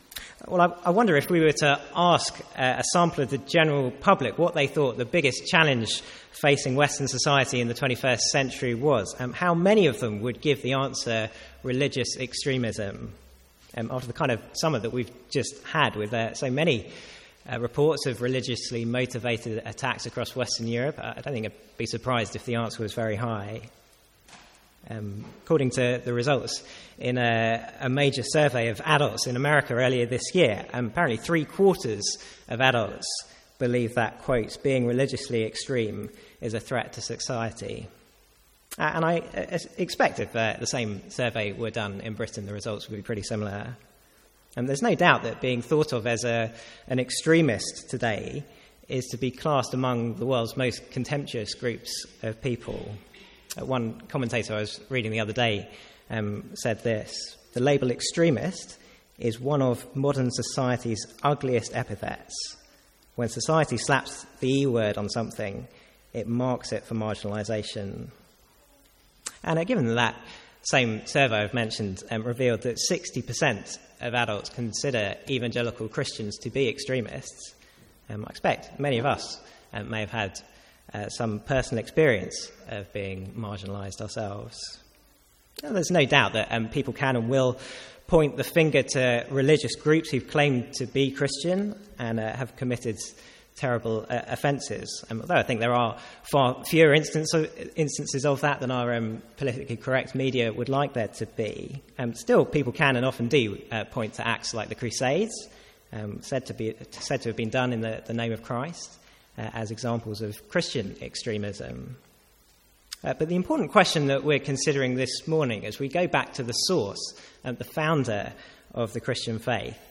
From the Sunday morning series in Luke.